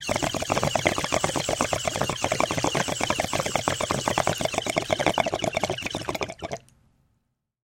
Звуки бонга
Затянулись подольше во все легкие